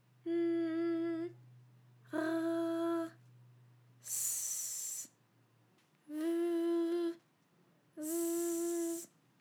ALYS-DB-001-FRA - First, previously private, UTAU French vocal library of ALYS
-n-r-s-v-z.wav